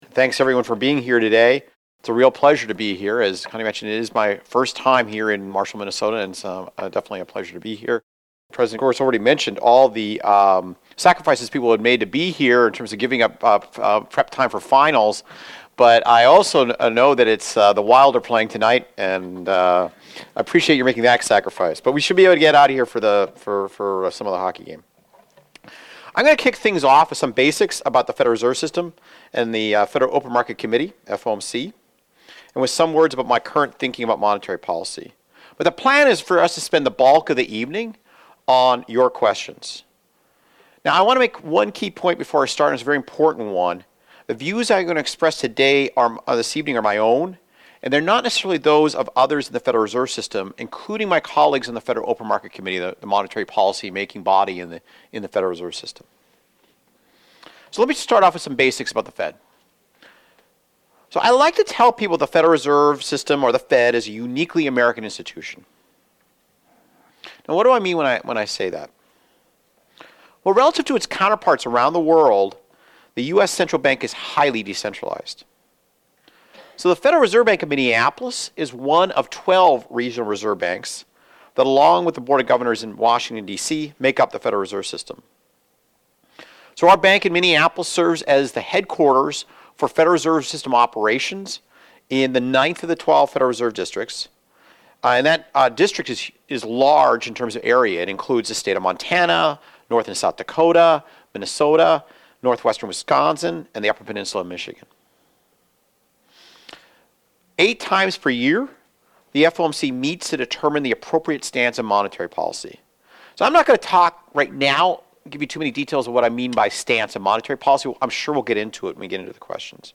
Remarks and QA (video) Remarks and QA (audio) Thanks for the introduction, and thank you for the invitation to join you here today.